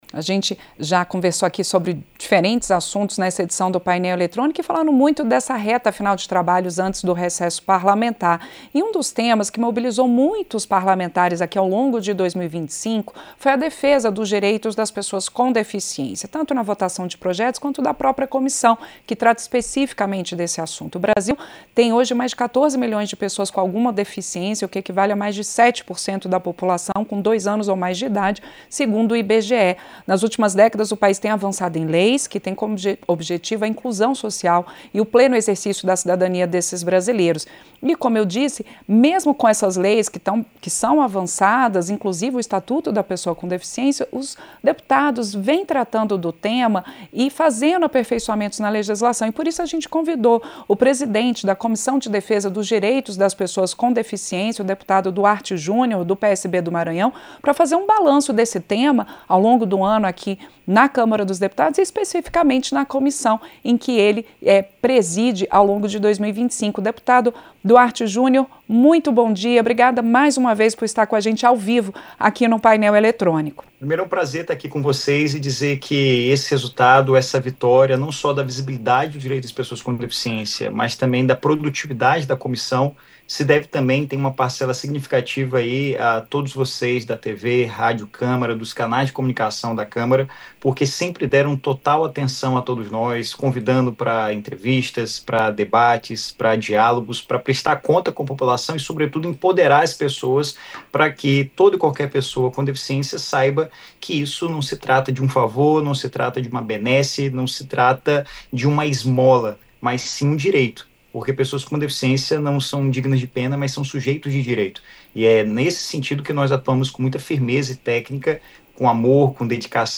A Comissão de Defesa dos Direitos das Pessoas com Deficiência, na Câmara dos Deputados, zerou a pauta em 2025, com recorde de aprovações de propostas em dez anos desde a criação do colegiado. Os números foram destacados pelo presidente da comissão, deputado Duarte Jr. (PSB-MA), em entrevista de balanço ao Painel Eletrônico (17).
Entrevista - Dep. Duarte Jr. (PSB-MA)